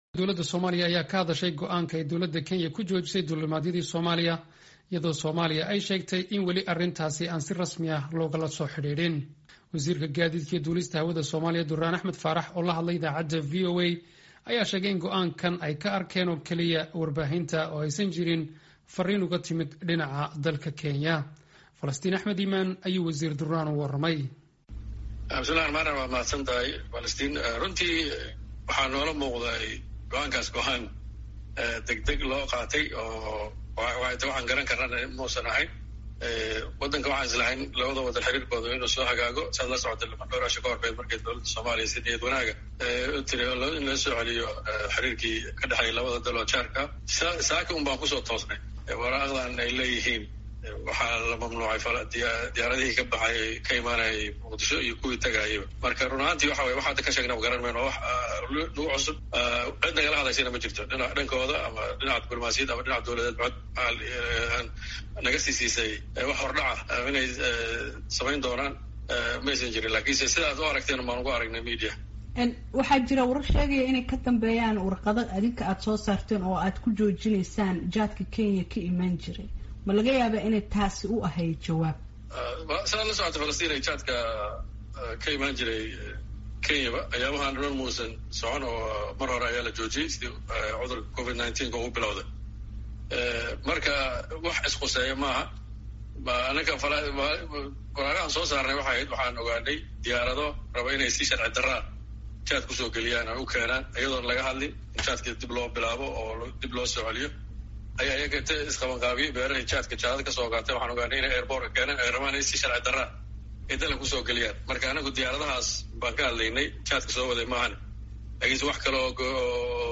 Wareysi: Wasiir Duraan Faarax oo ka hadlay go'aankii Kenya ku hakisay duulimaadyada